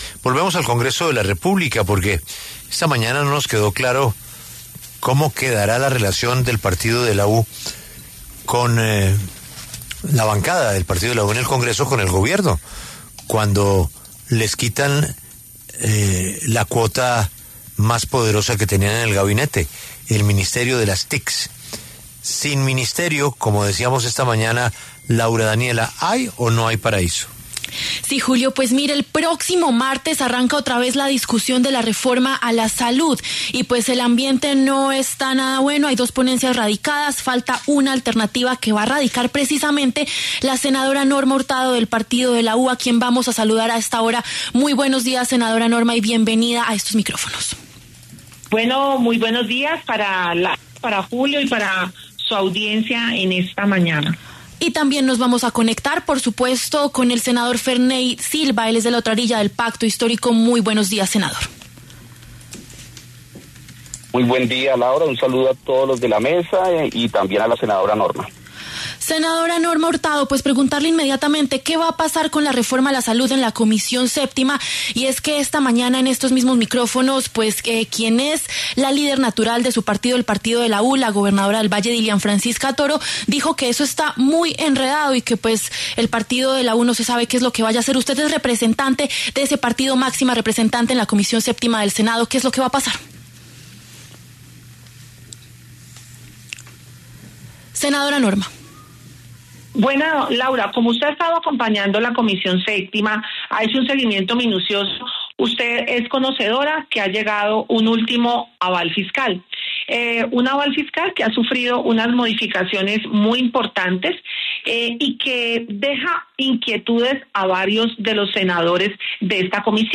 Los senadores de la Comisión Séptima del Senado, Norma Hurtado (Partido de la U) y Ferney Silva (Pacto Histórico) pasaron por los micrófonos de La W. El próximo martes se agendará la discusión del proyecto.